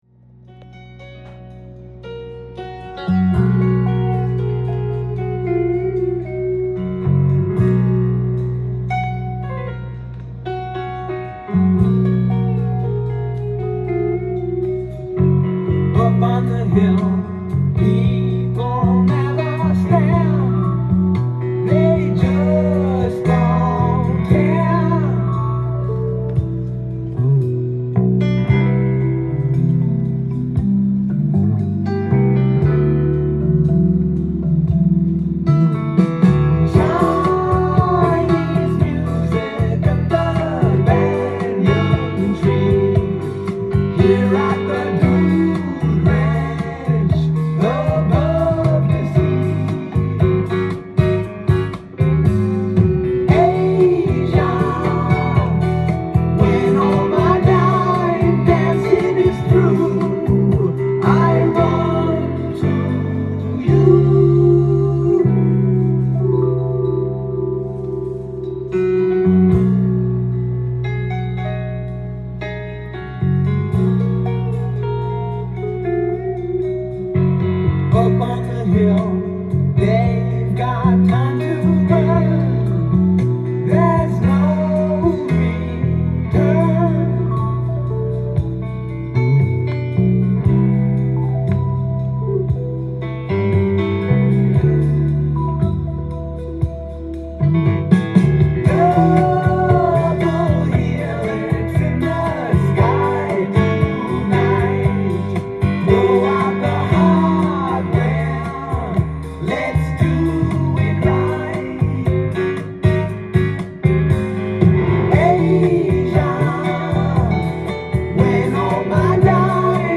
ジャンル：AOR
店頭で録音した音源の為、多少の外部音や音質の悪さはございますが、サンプルとしてご視聴ください。
音が稀にチリ・プツ出る程度